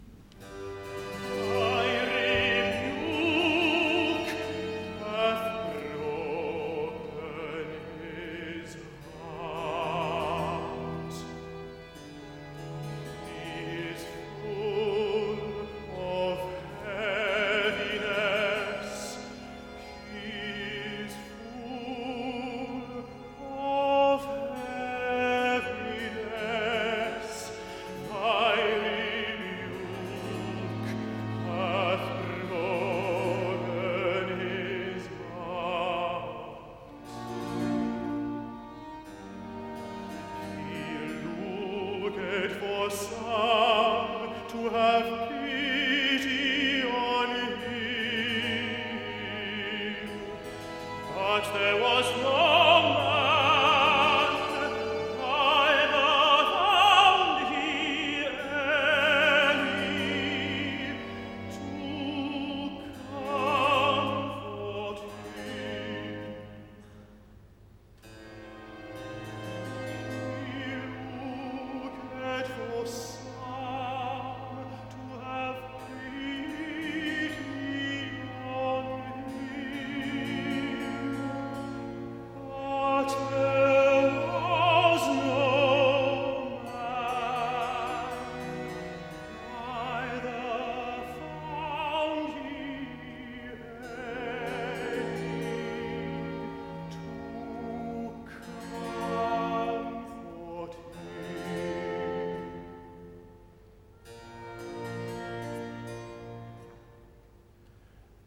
Recitative-tenor